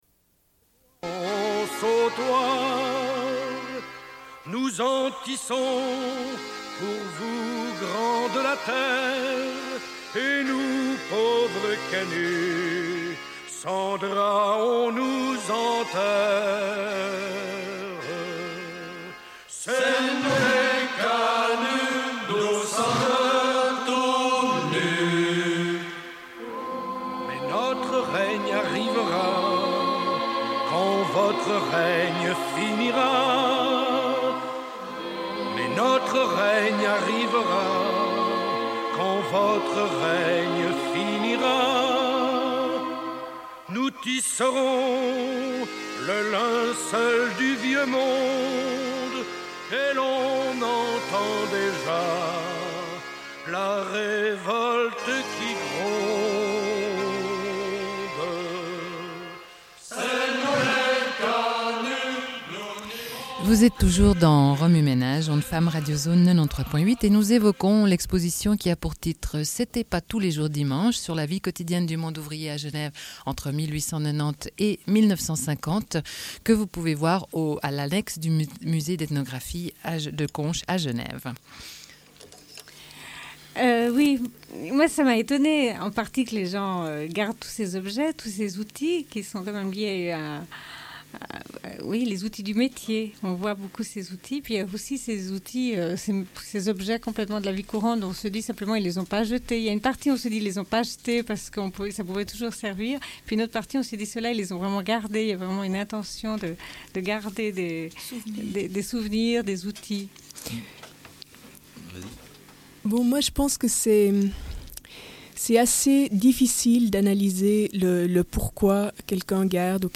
Une cassette audio, face B29:07